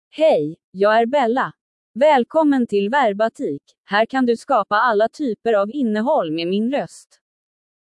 Bella — Female Swedish (Sweden) AI Voice | TTS, Voice Cloning & Video | Verbatik AI
Bella is a female AI voice for Swedish (Sweden).
Voice sample
Female
Bella delivers clear pronunciation with authentic Sweden Swedish intonation, making your content sound professionally produced.